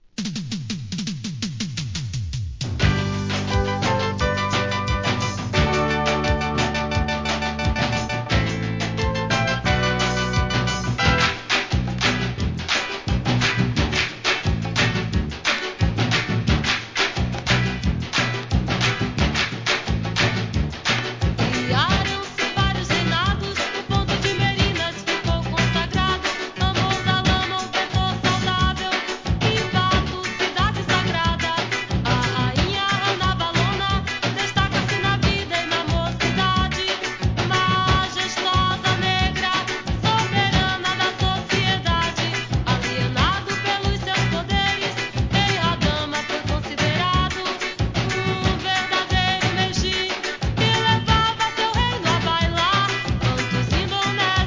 ブラジル産レゲエ